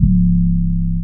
808_ChxpUp.wav